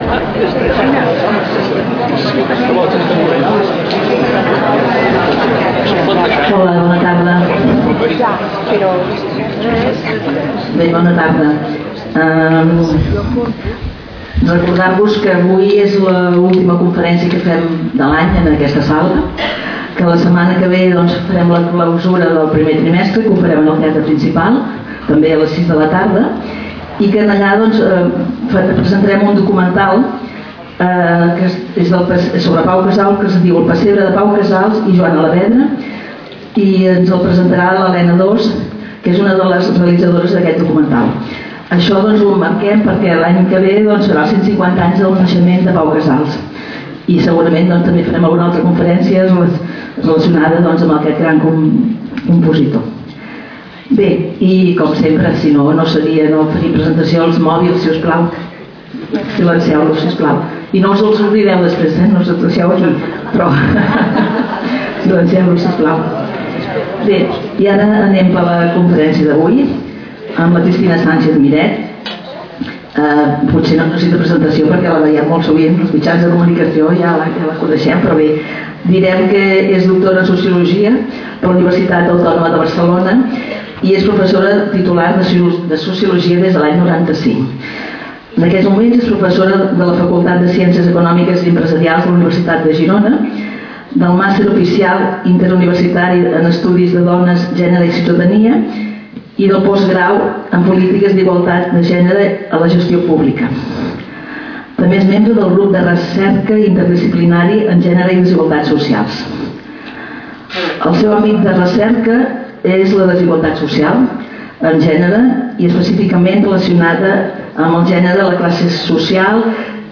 Lloc: Casal de Joventut Seràfica
Categoria: Conferències